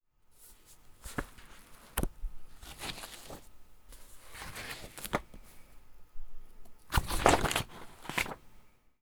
paper-sketchbook-page-flips-1.wav